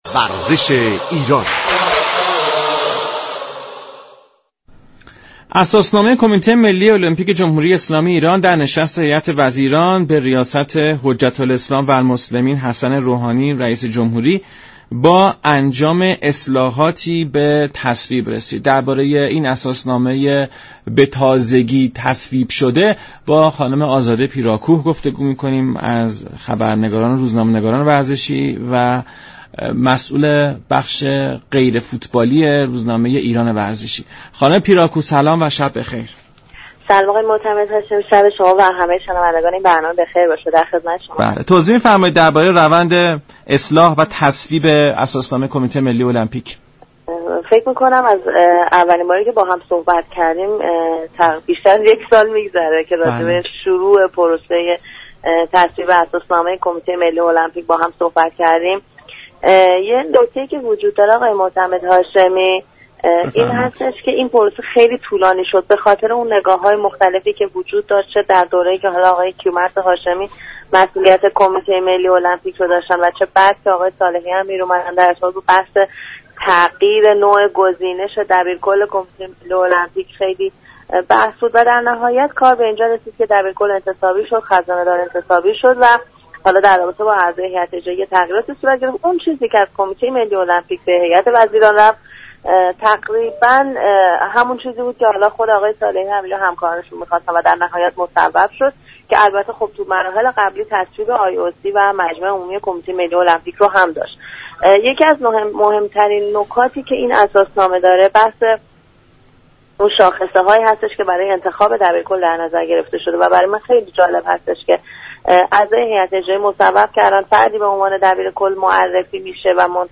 گفت و گو